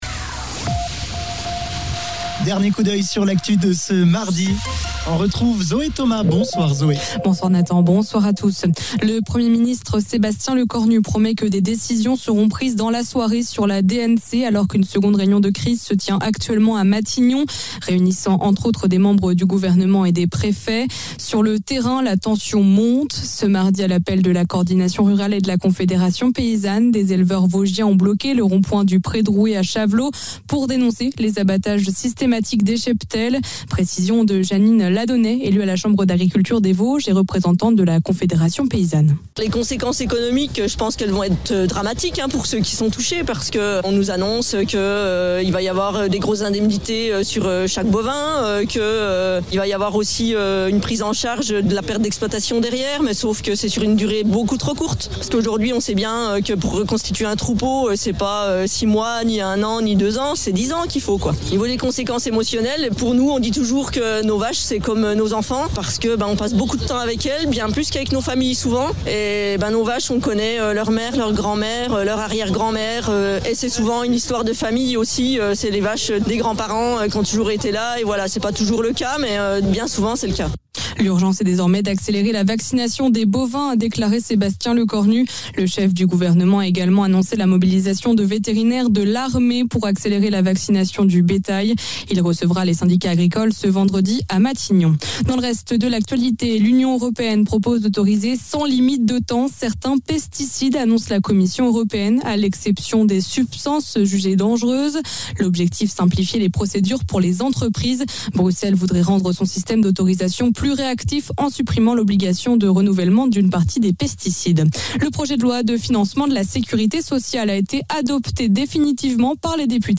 Le flash de 19h